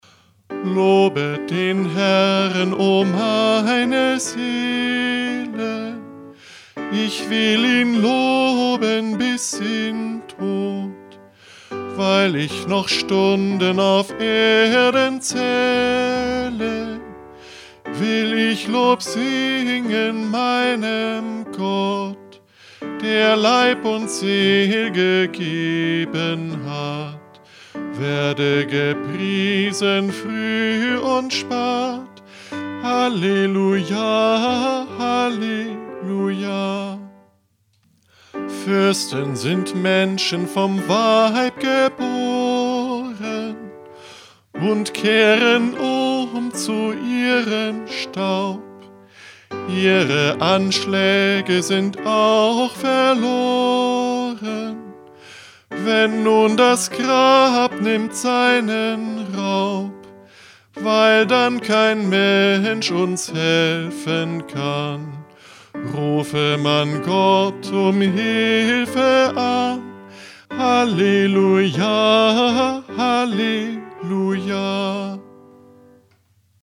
Lob- und Danklied.
Melodie: Ansbach 1665, Appendix zu Neu-vermehrte Christlich Seelen-Harfe (1664);
Liedvortrag